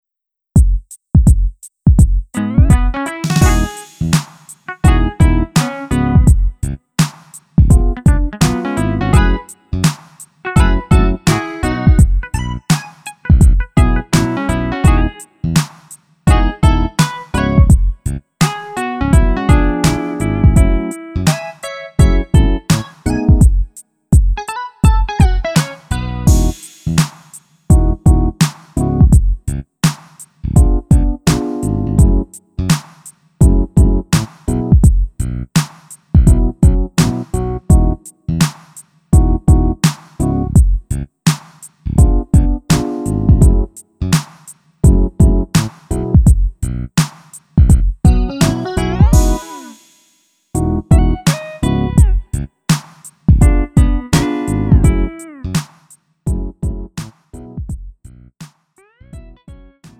음정 원키 3:55
장르 구분 Lite MR